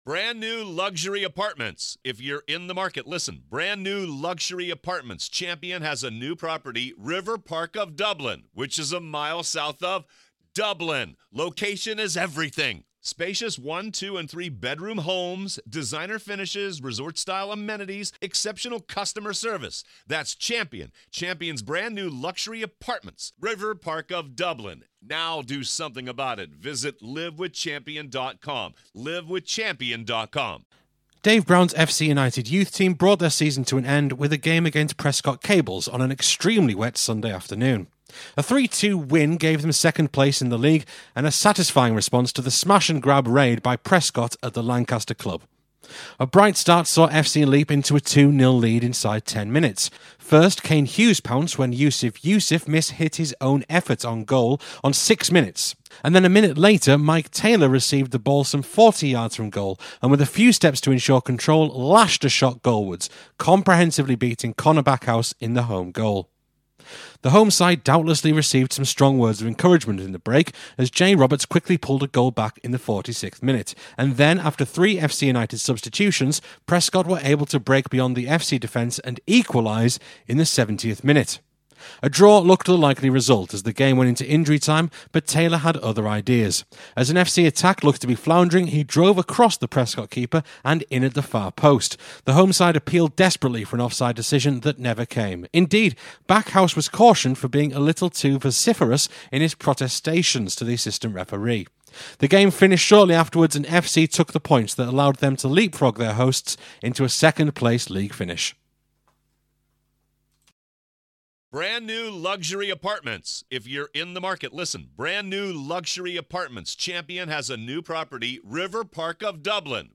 Youth Match Report - Prescot Cables (a)